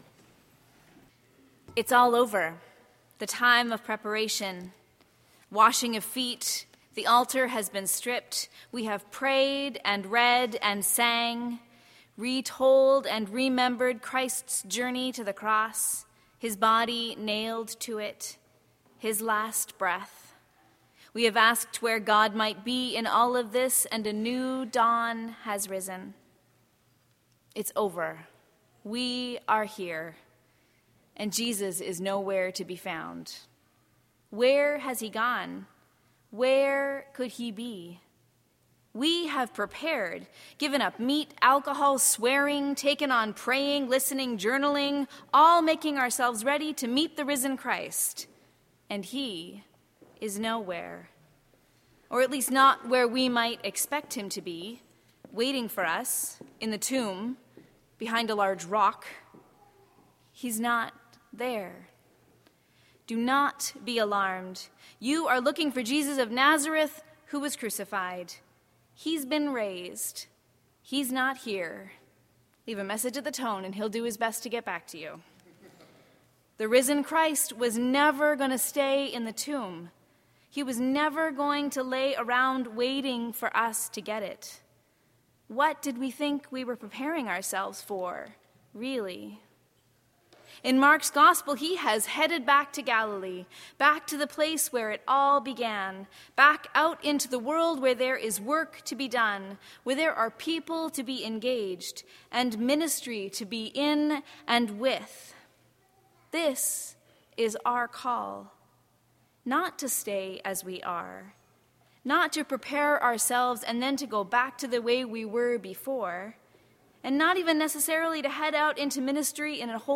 Sermons | Christ Church Cathedral Vancouver BC